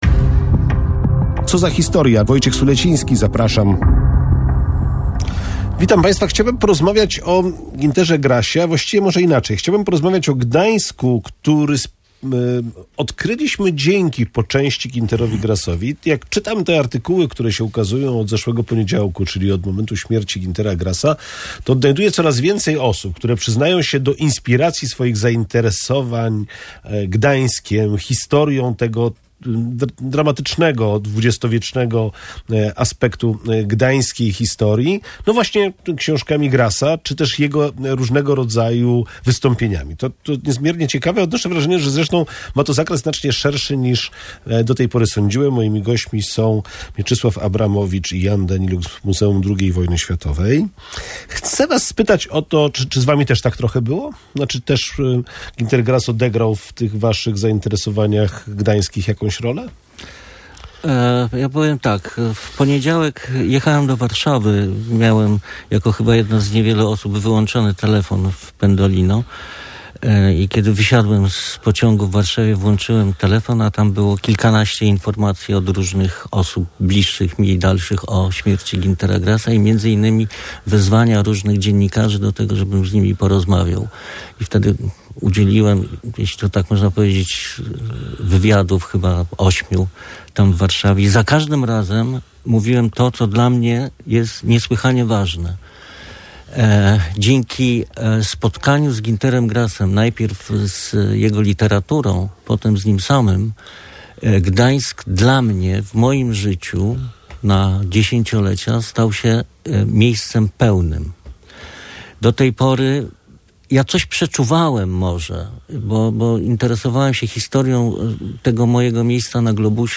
Gdańsk odkryty w książkach Güntera Grassa stał się inspiracją do dyskusji historyków w Radiu Gdańsk.